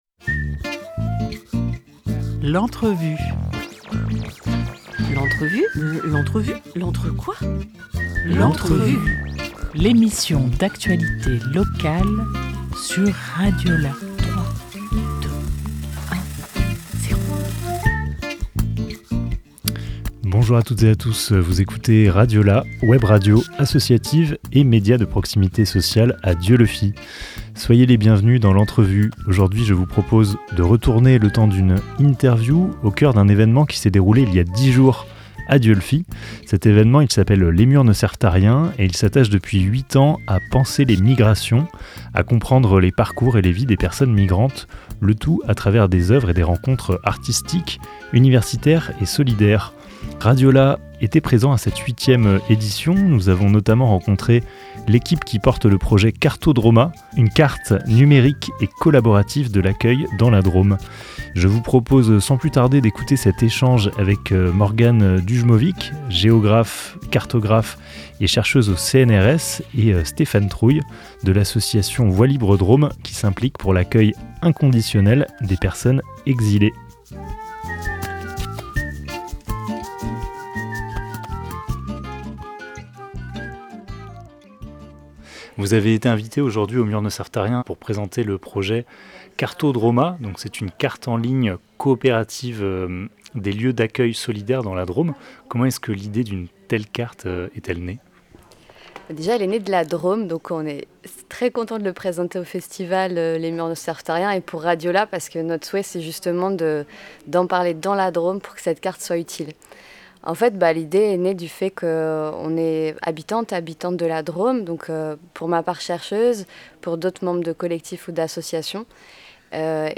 1 octobre 2024 10:49 | Interview